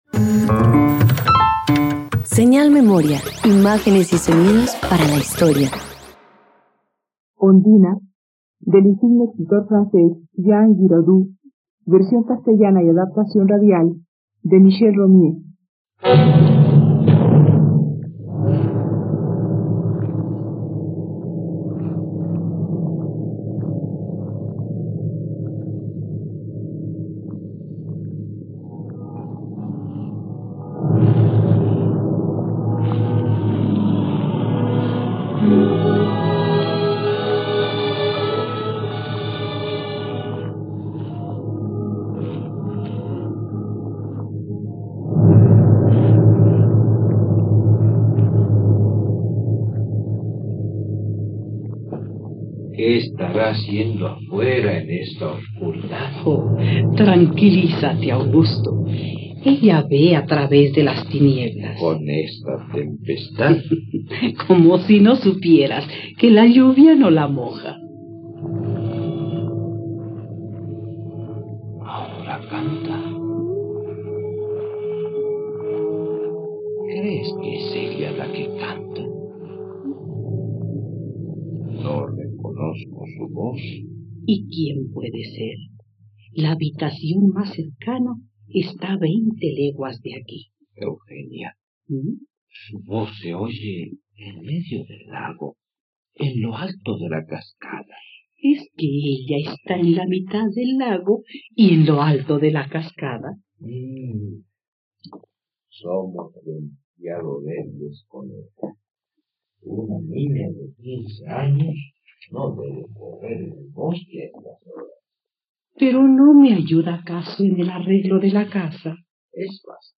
Ondina - Radioteatro dominical | RTVCPlay
..Radioteatro. Escucha la adaptación de la obra "Ondina" del dramaturgo francés Jean Giraudoux, disponible en la plataforma de streaming RTVCPlay.